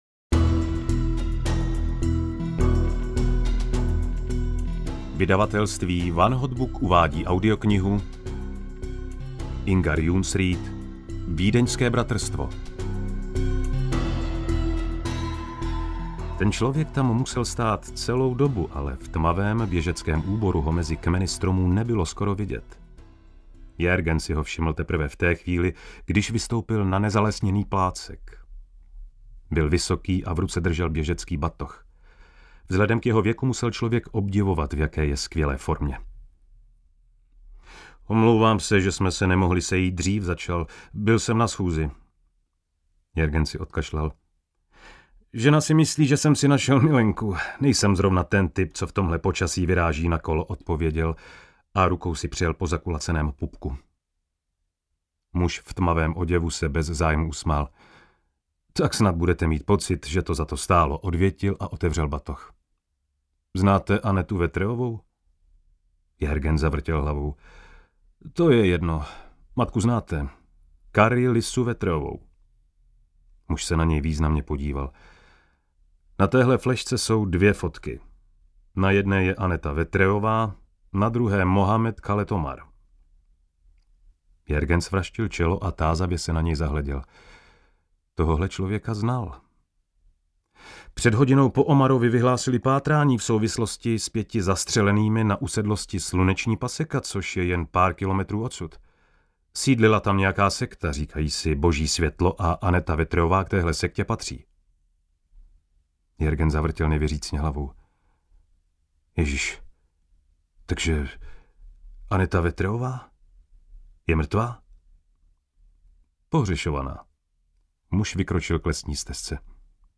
Ukázka z knihy
videnske-bratrstvo-audiokniha